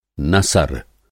Enviar uma foto Sugerir uma tradução Adicione às suas revisões Eliminar das suas revisões नसर नसर prosa Aprenda Hindi com falantes nativos Nova pesquisa? pesquisa Pesquisar com voz ... ou folhear o dicionário Descubra a Índia